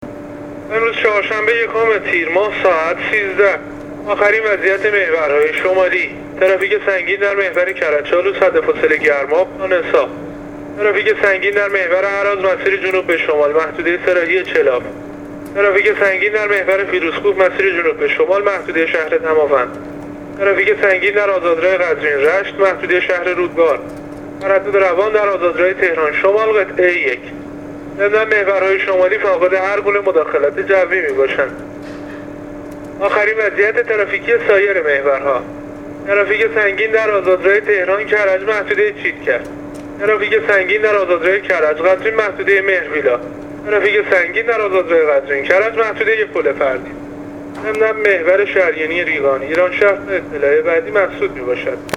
گزارش رادیو اینترنتی از آخرین وضعیت ترافیکی جاده‌ها تا ساعت ۱۳ یکم تیر؛